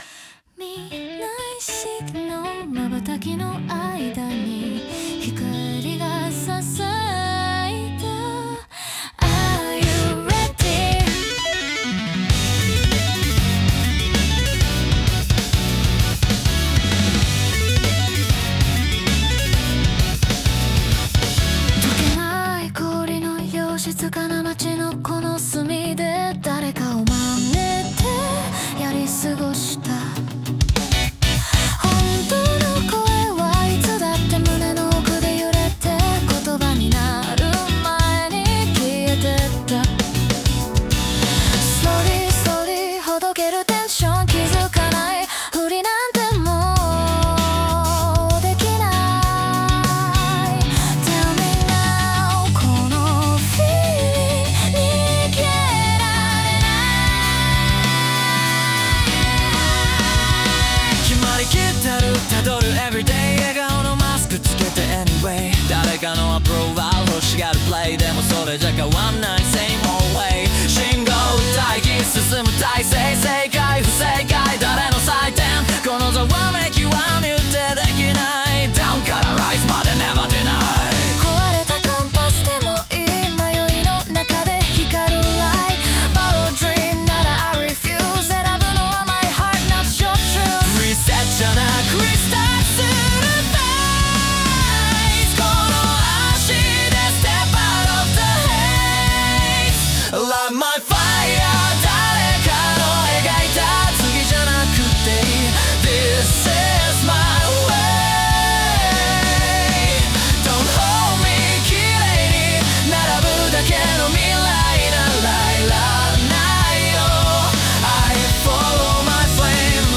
女性ボーカル
イメージ：J-ROCK,女性ボーカル,男性ボーカル,かっこいい,複雑,シューゲイザー